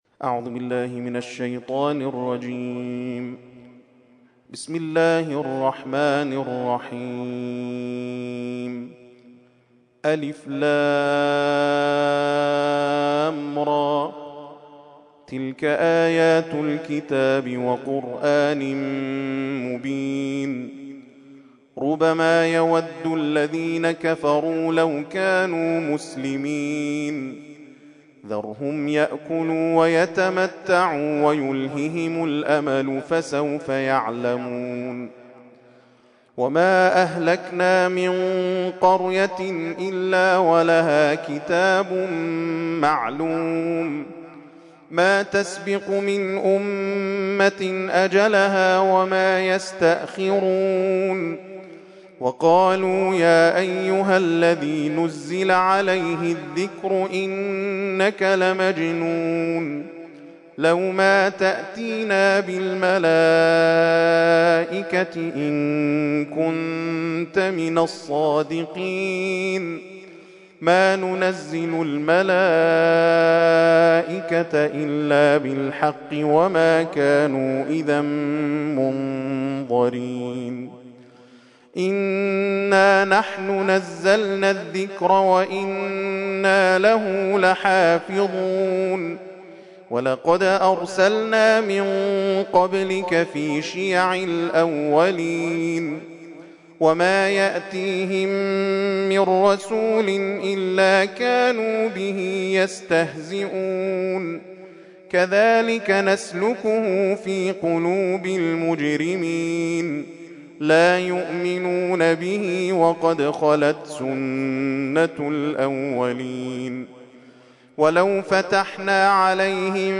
ترتیل خوانی جزء ۱۴ قرآن کریم در سال ۱۳۹۴